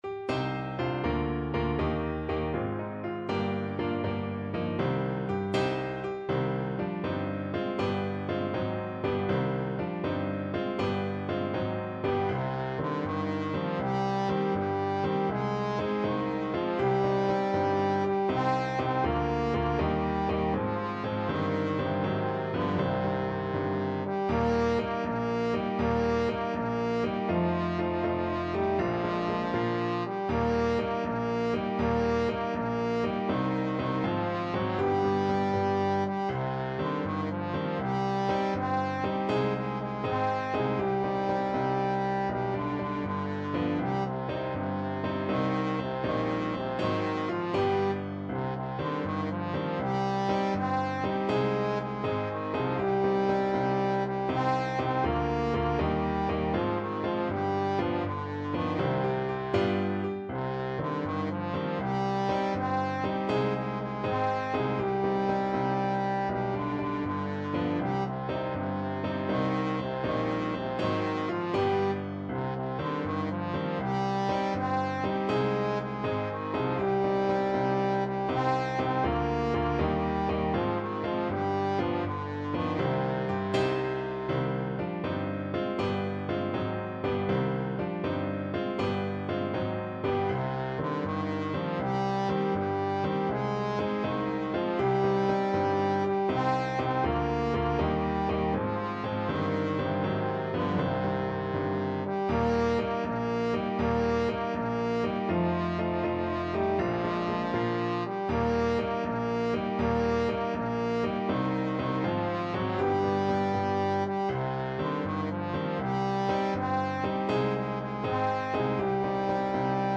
6/8 (View more 6/8 Music)
Brightly, but not too fast
Classical (View more Classical Trombone Music)